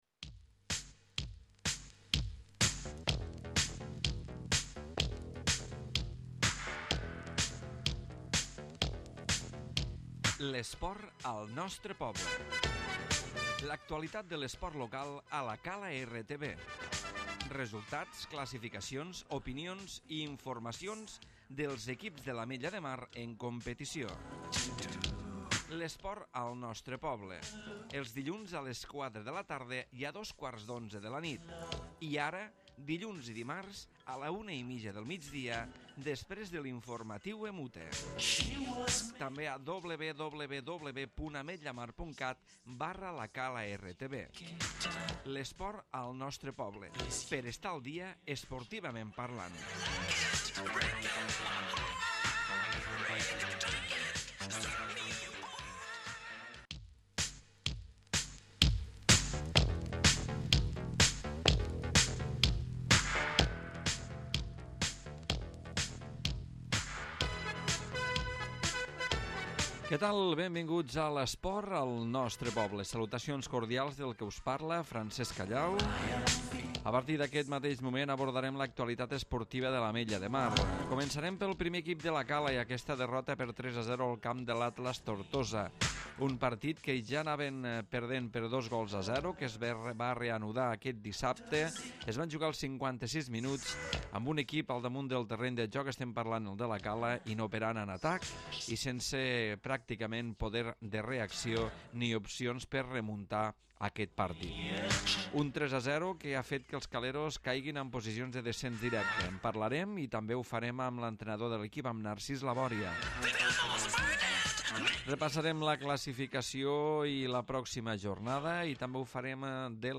Avui programa dedicat a la derrota del 1r equip de La Cala al camp de l'Atlas Tortosa, amb entrevista